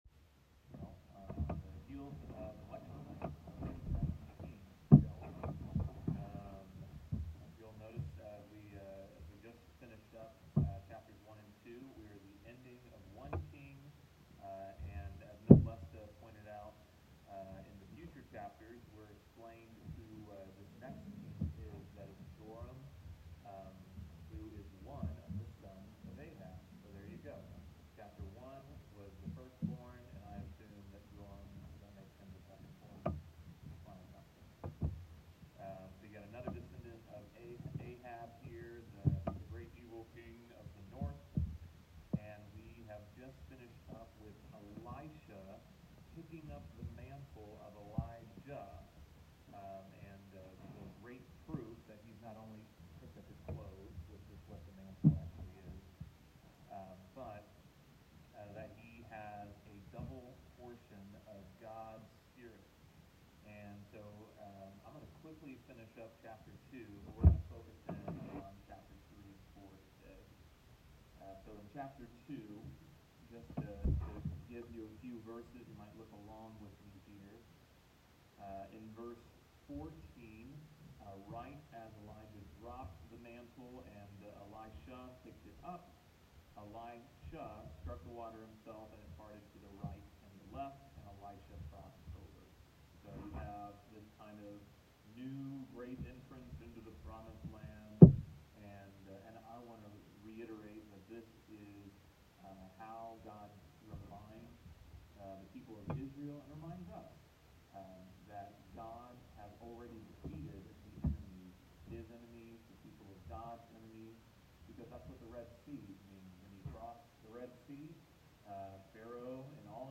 Wednesday Morning Bible Study 2 Kings 3-4